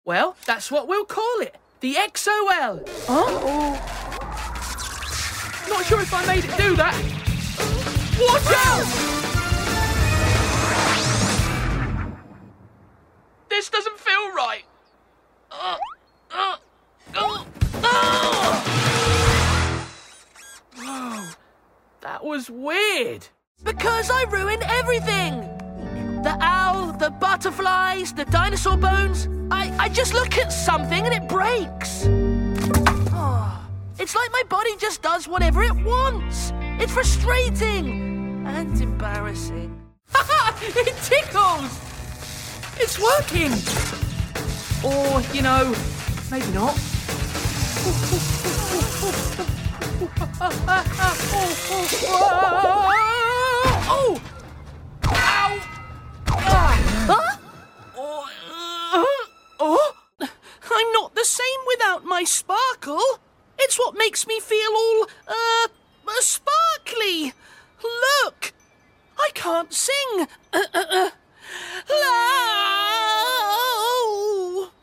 Teens-20s. Young, fun. Fresh with a light touch. Good singer. Home Studio.
Animation
• Skills: Sings, Young Voices